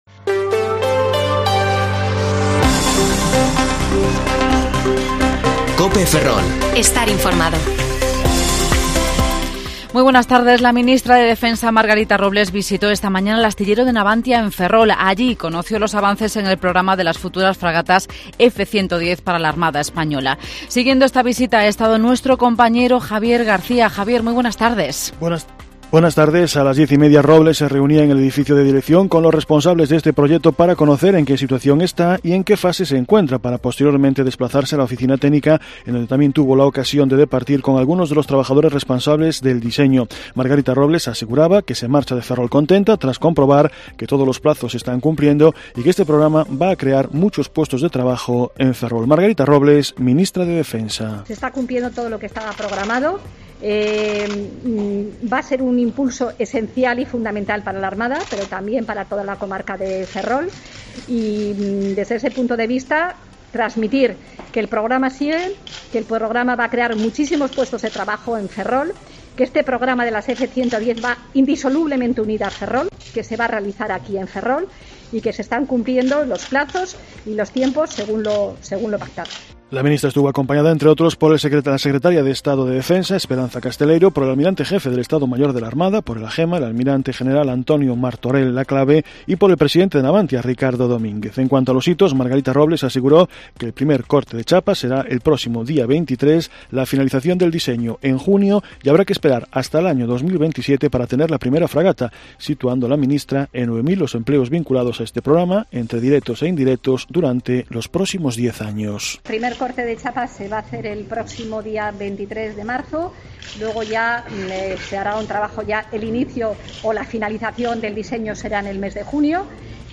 Informativo Mediodía COPE Ferrol 11/3/2022 (De 14,20 a 14,30 horas)